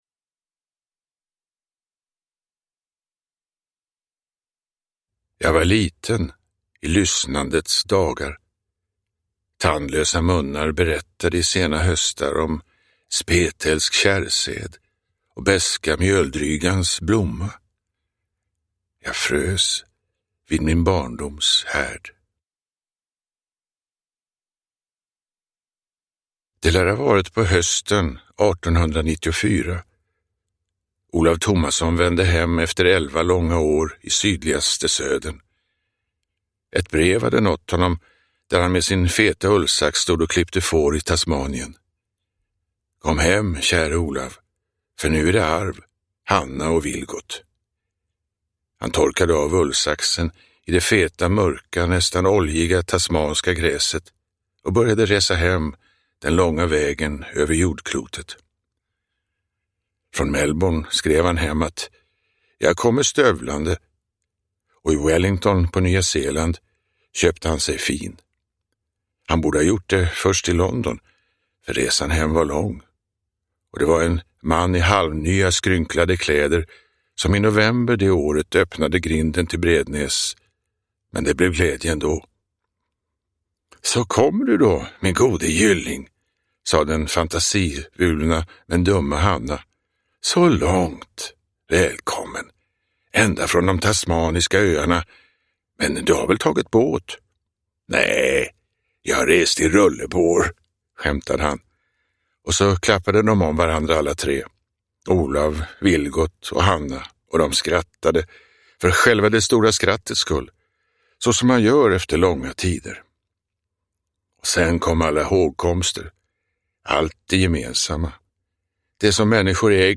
Nässlorna blomma – Ljudbok – Laddas ner
Uppläsare: Torsten Wahlund